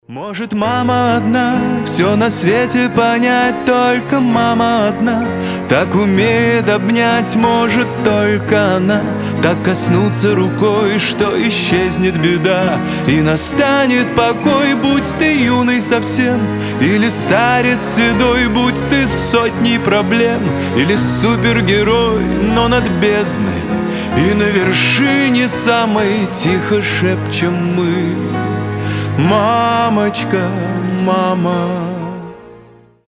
Нежные рингтоны
Поп